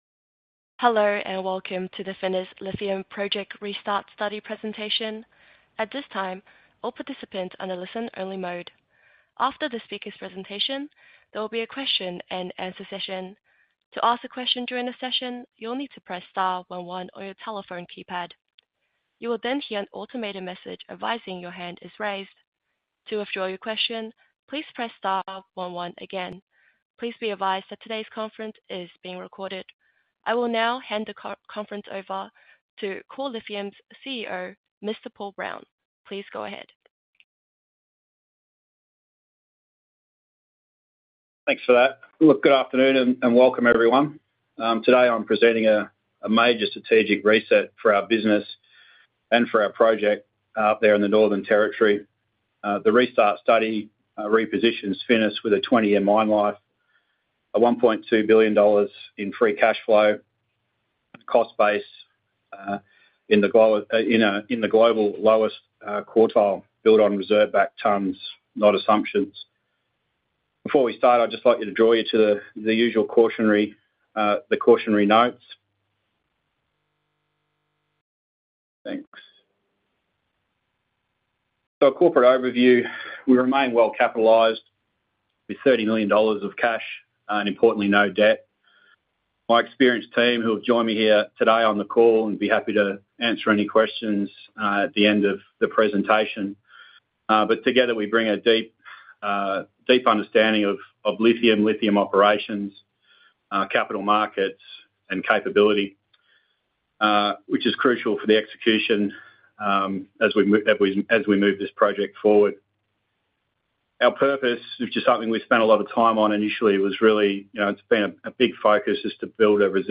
Finniss Restart Study Conference Call Recording